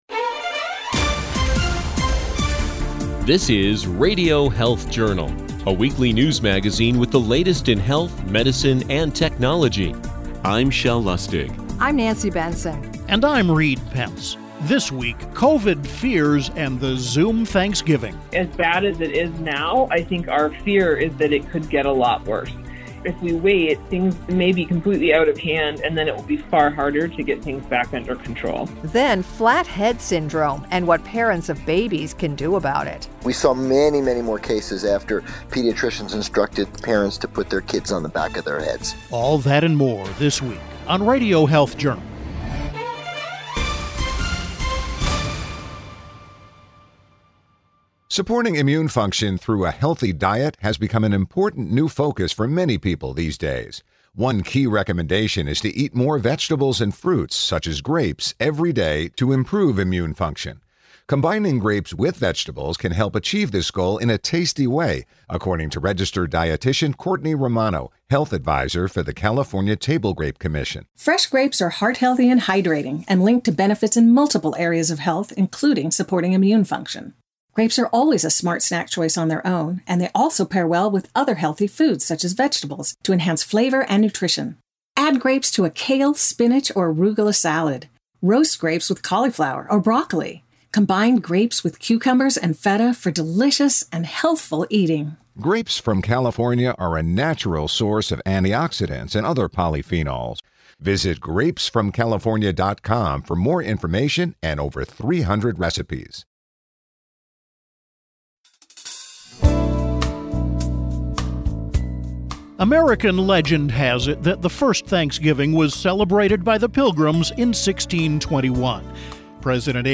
Radio Health Journal Interview on COVID transmission in restaurants and household gatherings